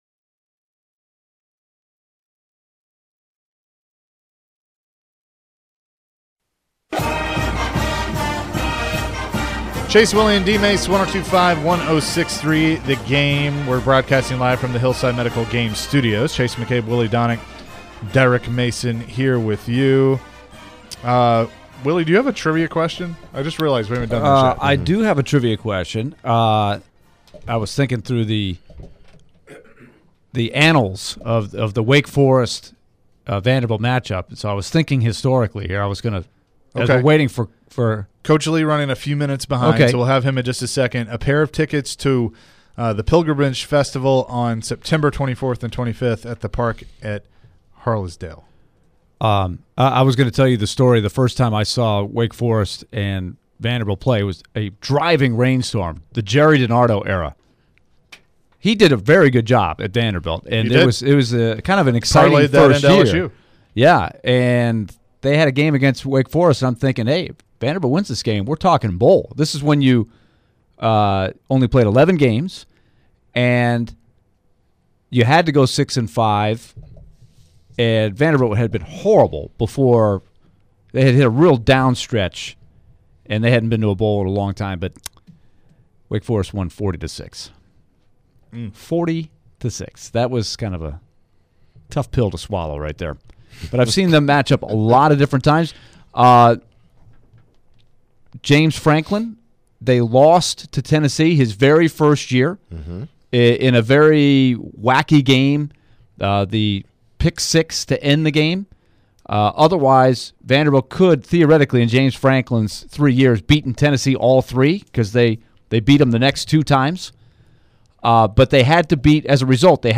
Full Interview